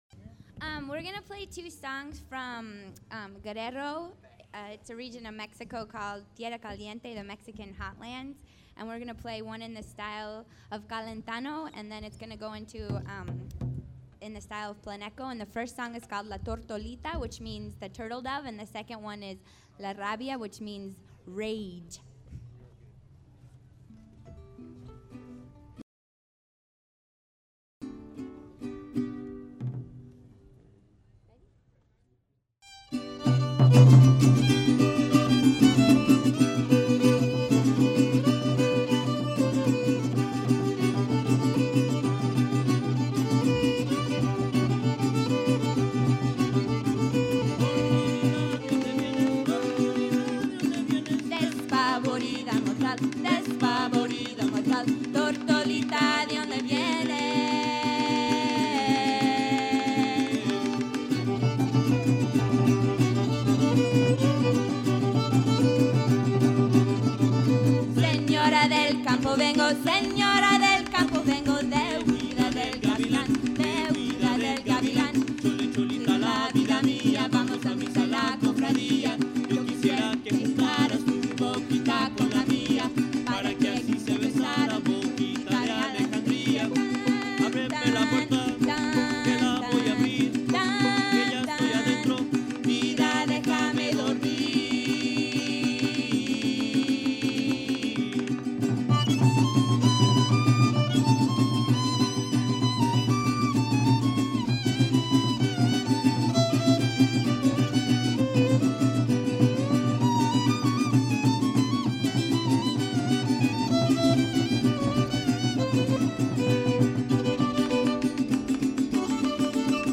Fiddle Teams / Open Division
Fiddle Teams opening round on Thursday in Giddings Plaza. Finals on Sunday on Folk & Roots main stage.